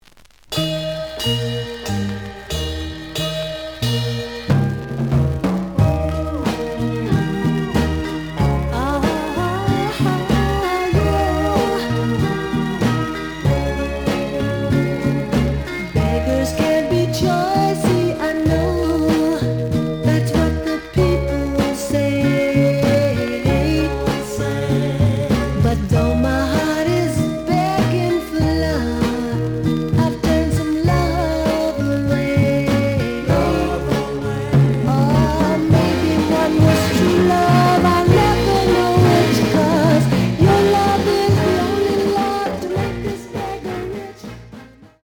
試聴は実際のレコードから録音しています。
The audio sample is recorded from the actual item.
●Genre: Soul, 60's Soul